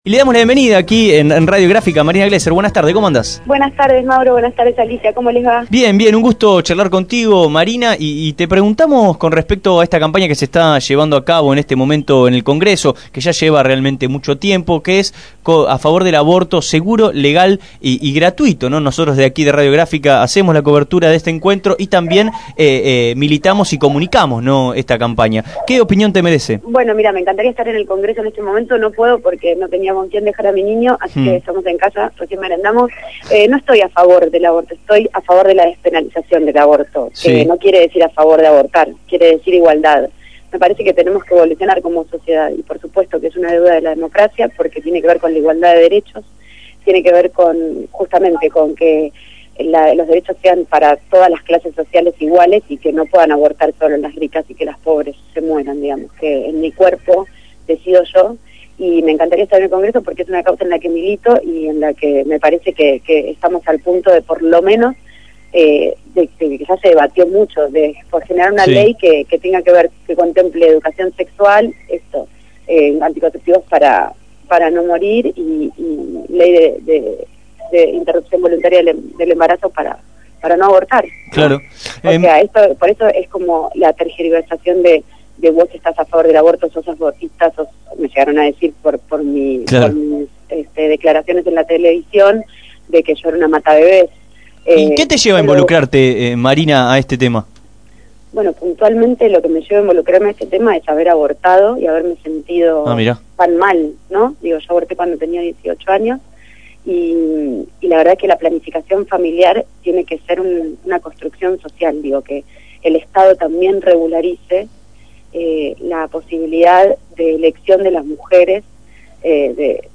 marinaMarina Glezer, actriz, paso por Radio Grafica y dialogo en el programa Abramos la Boca sobre la despenalización del Aborto, a raíz del encuentro que se realizo el lunes 29 de septiembre en Plaza Congreso con la consigna «Derecho al Aborto, una deuda de la Democracia»
En una entrevista
Marina-Glezer-ABORTO.mp3